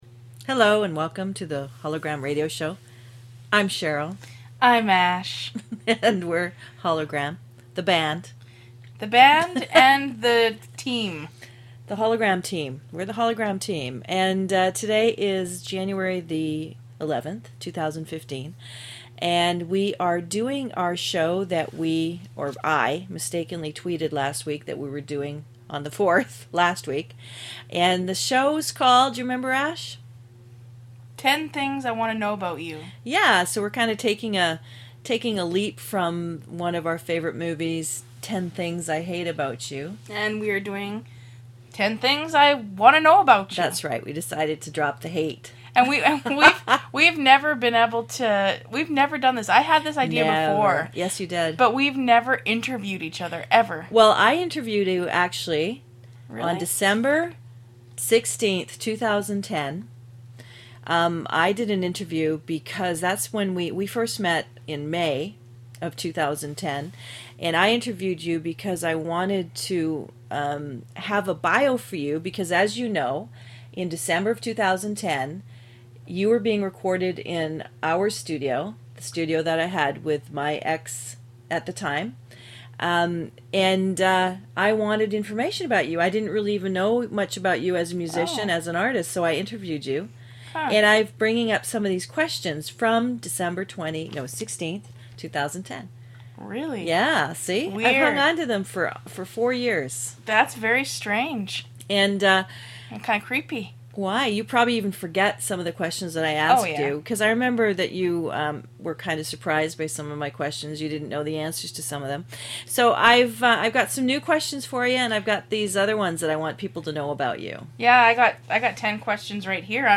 Talk Show Episode
They offer a unique radio show where they share their music, their sharp wit, and their visions for This planet.